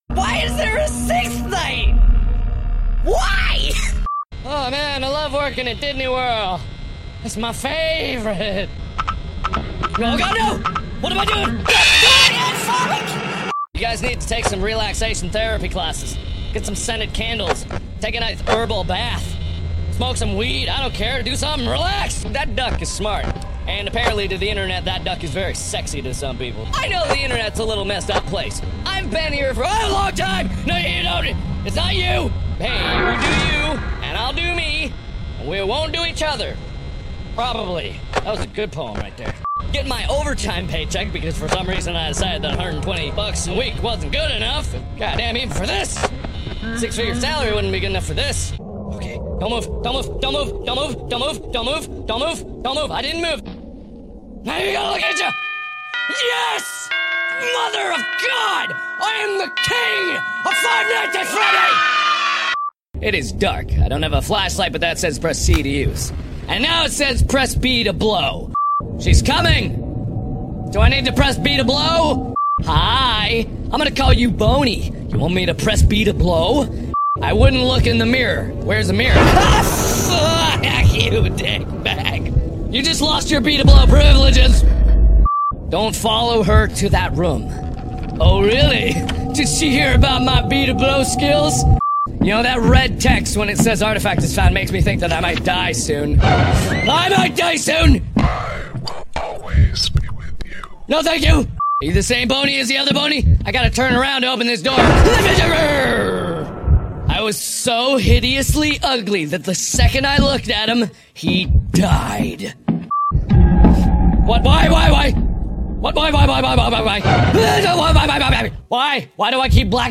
(warning: around the beginning there is a loud screech sound so watch out for that)